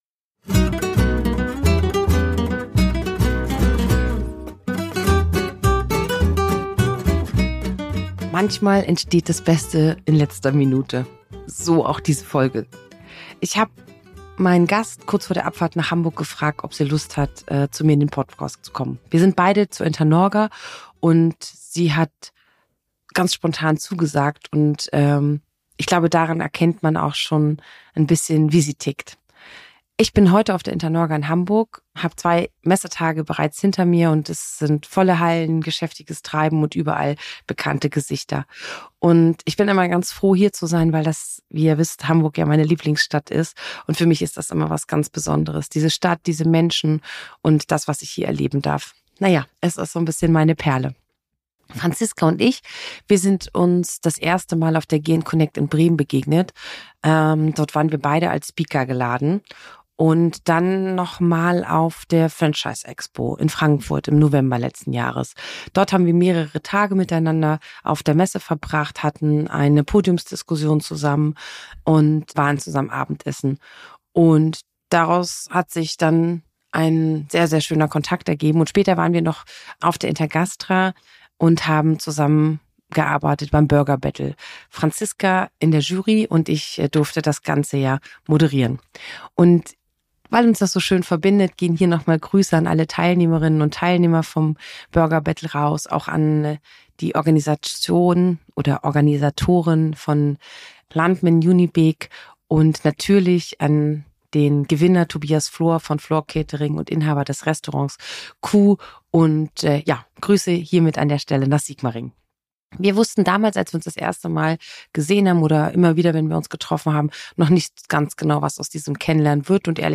In dieser Folge von Kirsch Royal sitze ich live auf der Internorga-Messe in Hamburg und habe jemanden zu Gast im Podcast, den ich erst vor Kurzem kennengelernt habe.